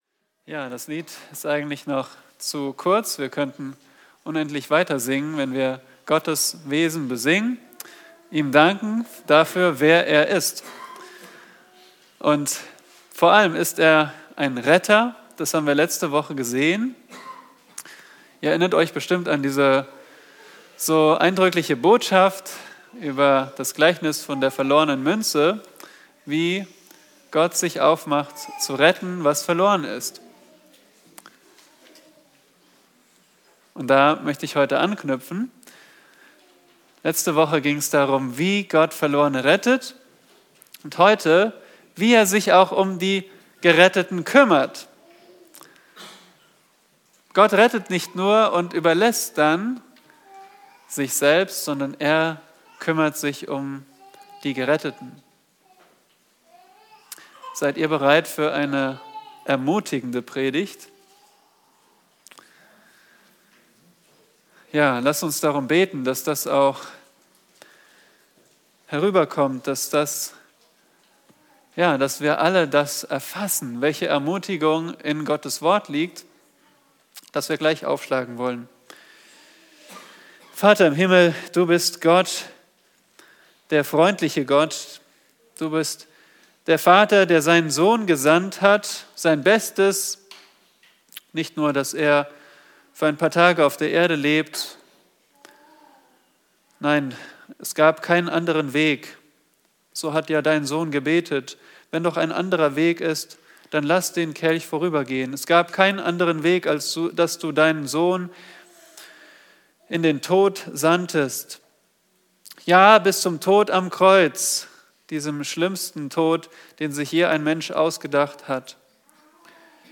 Predigten der Bibelgemeinde Berlin